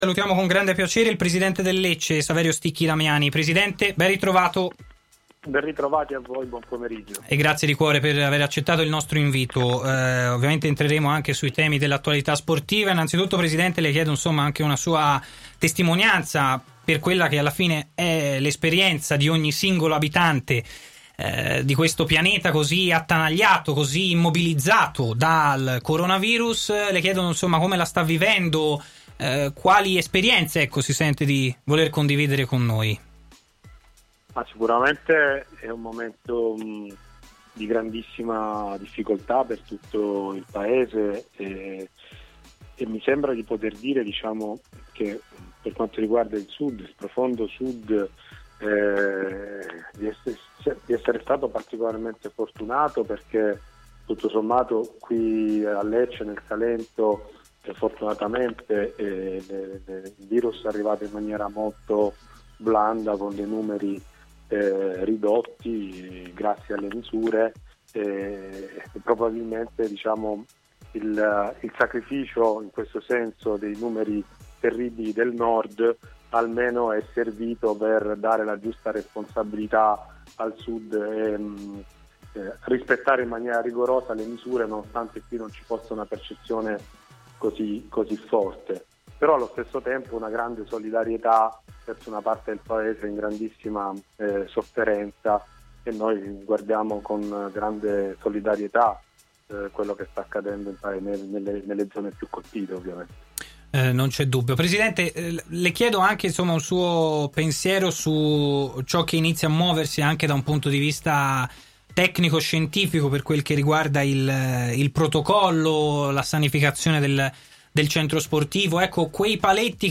si è collegato in diretta con Stadio Aperto, trasmissione in onda su TMW Radio